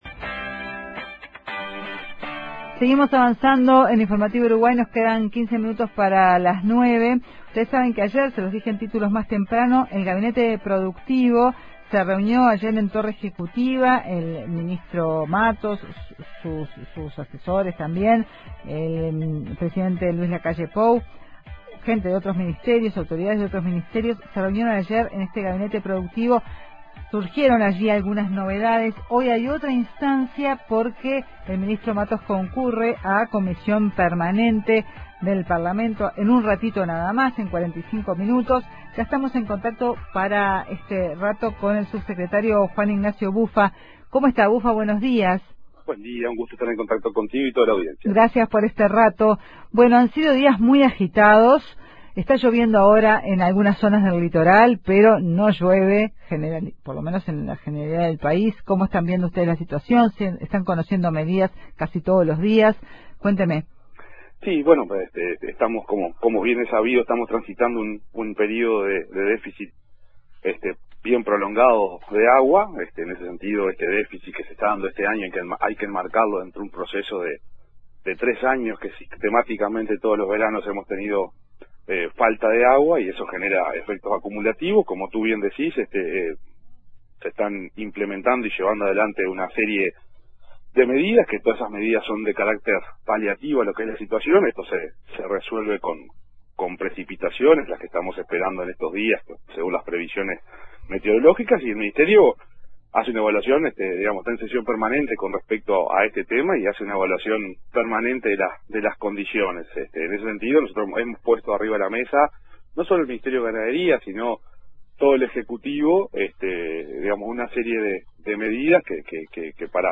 Subsecretario de Ganadería, Ignacio Buffa, dijo que no se descartan nuevas medidas para atender a productores afectados